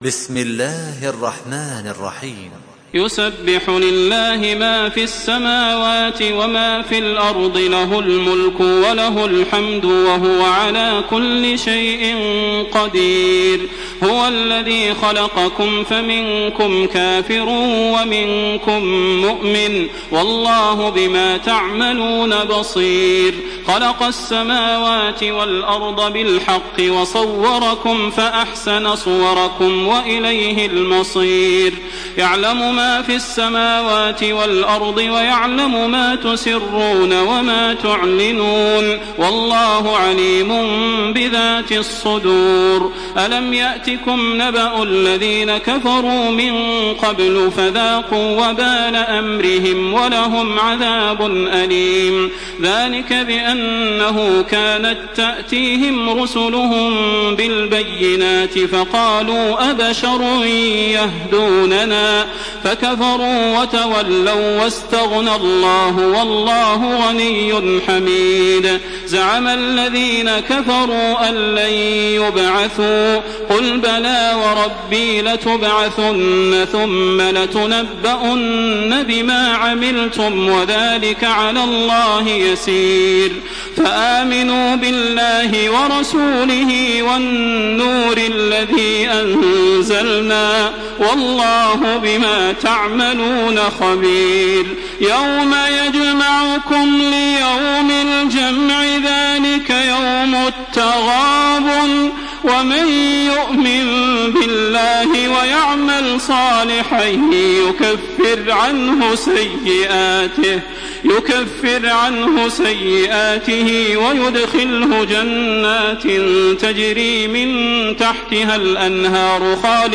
Surah At-Taghabun MP3 by Makkah Taraweeh 1427 in Hafs An Asim narration.
Murattal Hafs An Asim